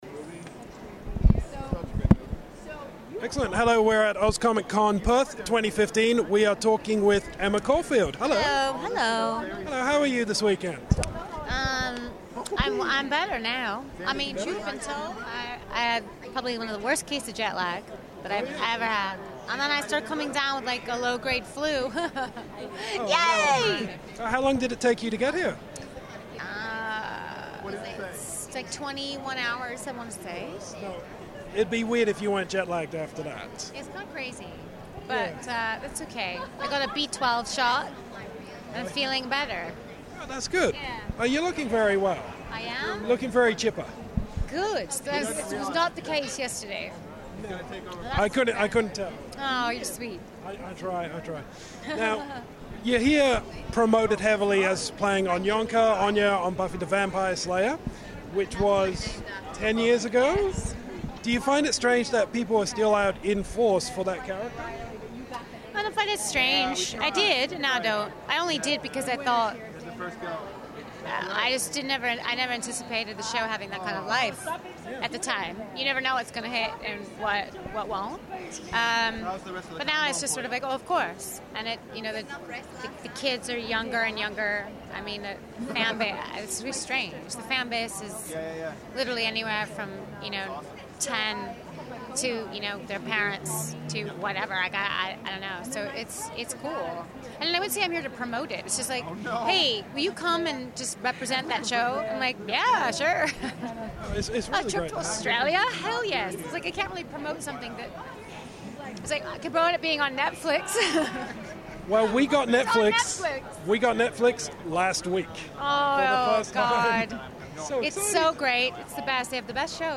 This was an awesome Oz ComicCon for Buffy fans!
emma-caulfield-house-of-geekery-oz-comiccon.mp3